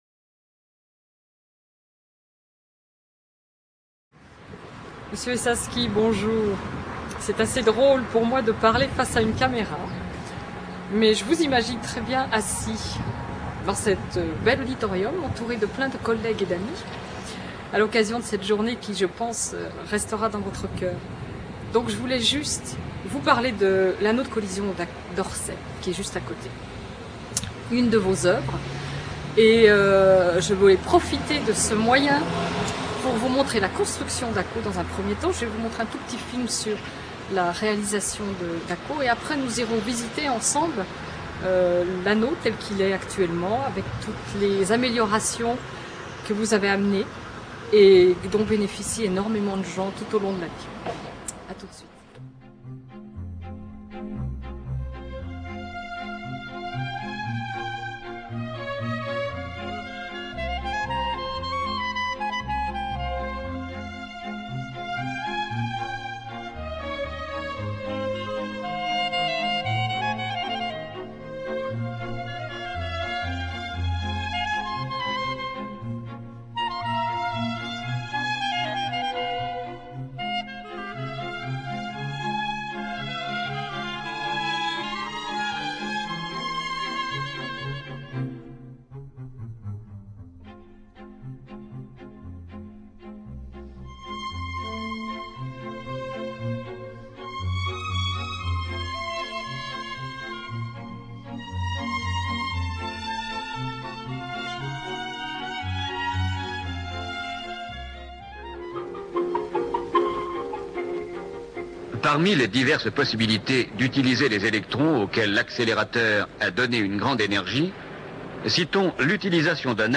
Amphi Pierre Lehmann (LAL)